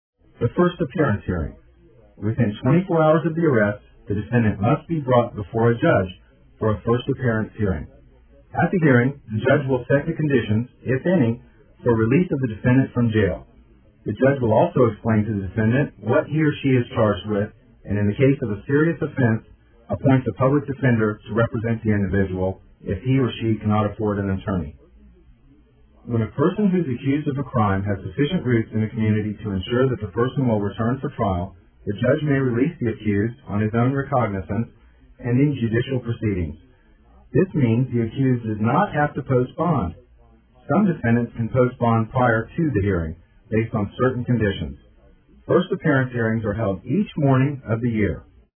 DUI Progression Described By a Board Certified Lawyer Go Over Each Step of a DUI in Tampa Courts